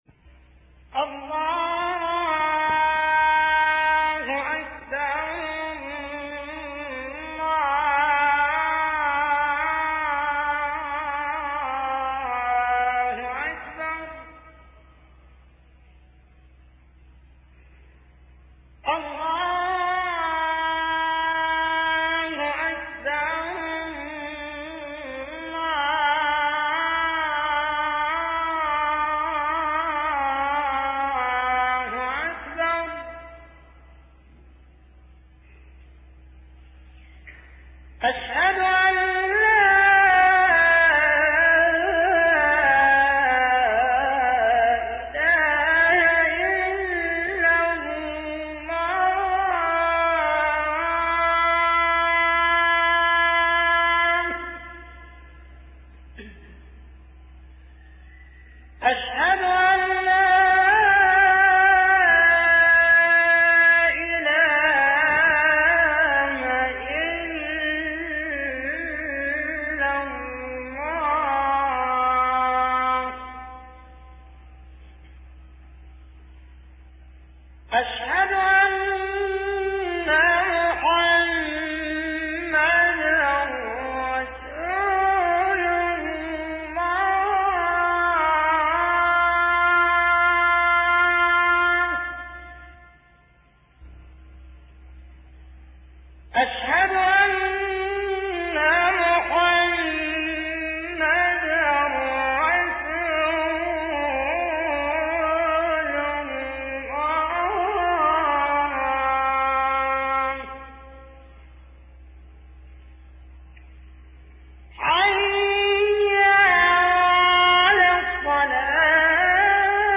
أناشيد ونغمات
عنوان المادة أذان-2